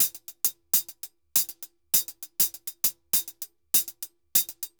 HH_Candombe 100_2.wav